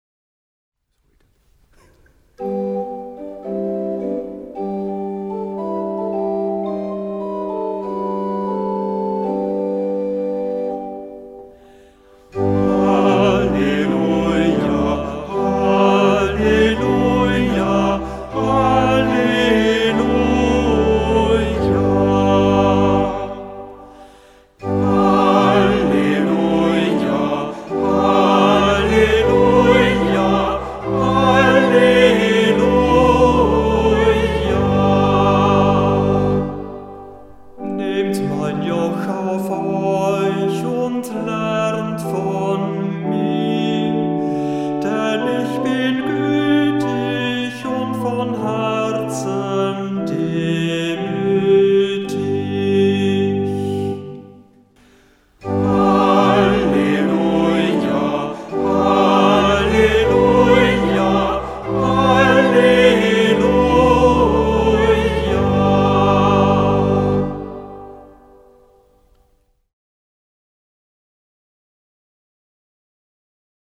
Kantor der Verse